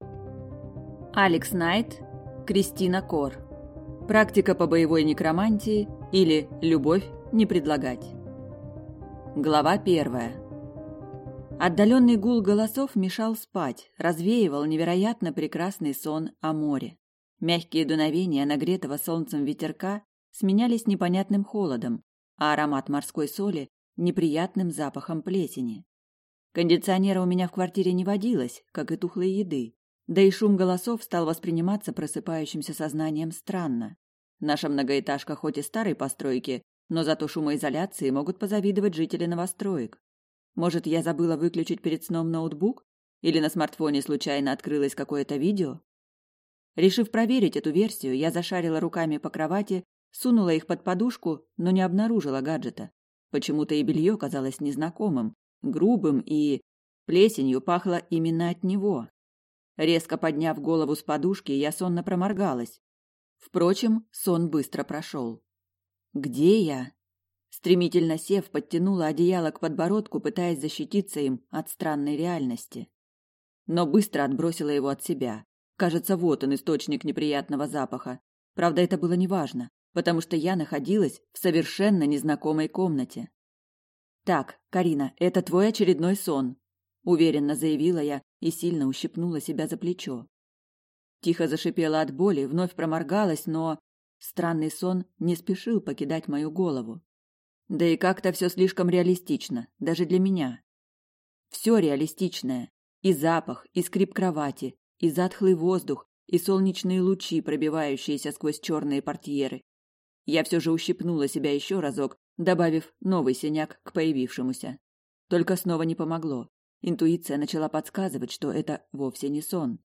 Аудиокнига Практика по боевой некромантии, или Любовь не предлагать | Библиотека аудиокниг